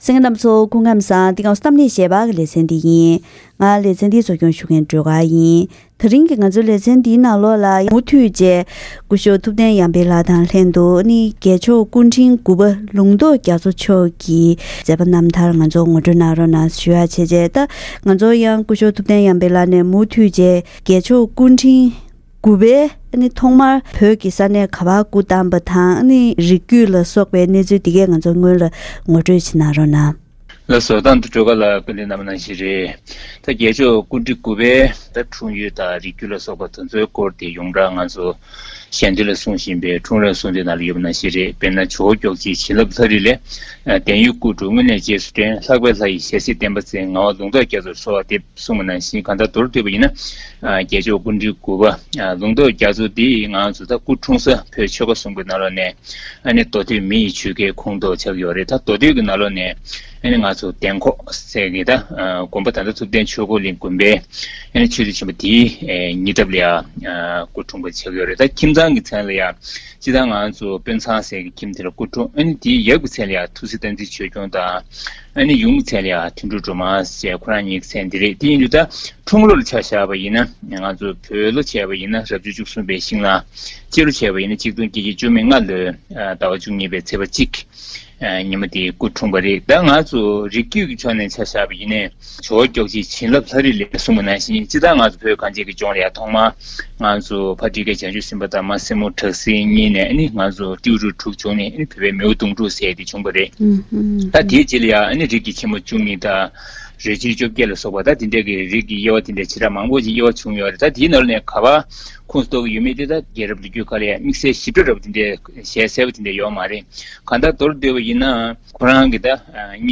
དེ་རིང་གི་གཏམ་གླེང་ཞལ་པར་ལེ་ཚན་ནང་༧རྒྱལ་མཆོག་སྐུ་ཕྲེང་རིམ་འབྱོན་གྱི་མཛད་པ་རྣམ་ཐར་ངོ་སྤྲོད་ཞུ་རྒྱུའི་ཁྲོད་ནས་༧རྒྱལ་མཆོག་སྐུ་ཕྲེང་དགུ་པ་ལུང་རྟོགས་རྒྱ་མཚོ་མཆོག་བོད་ཀྱི་མདོ་སྟོད་ཁུལ་དུ་སྐུ་འཁྲུངས་ཤིང་།